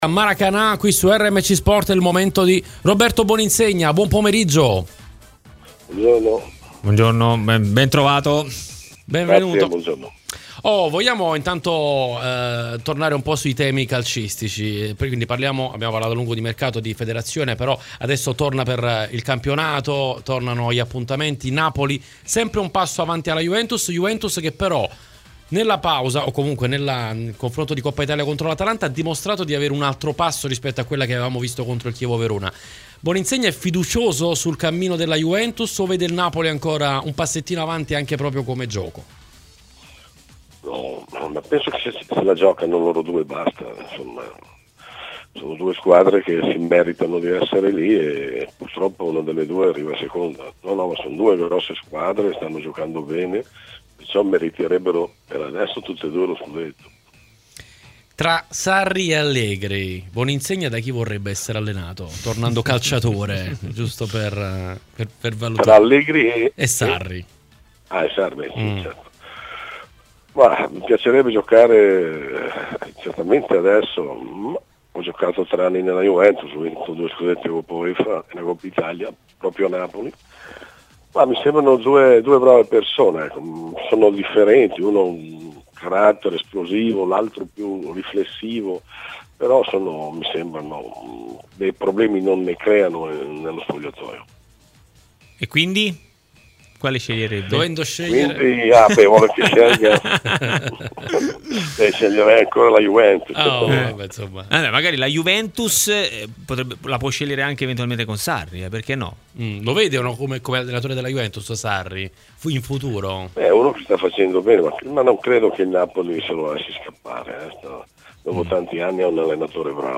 Roberto Boninsegna, ex calciatore, intervistato